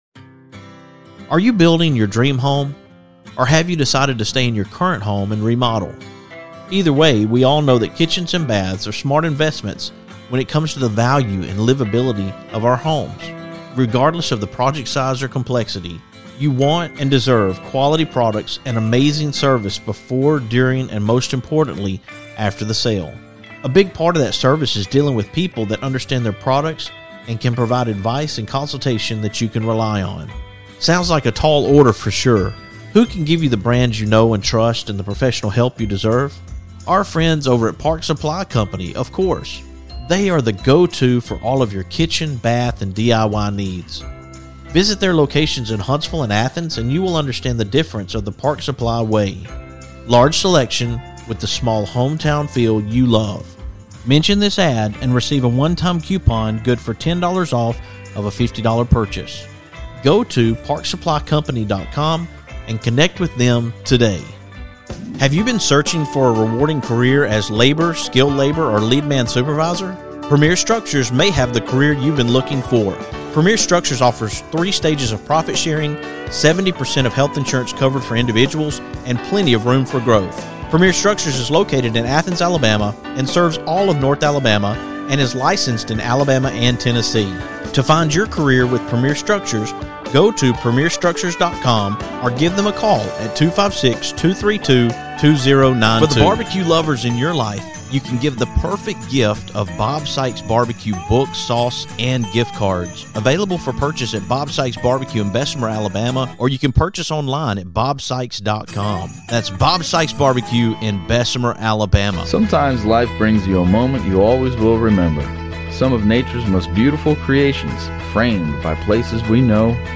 you'll hear from legendary country music singer/songwriter/musician Billy Dean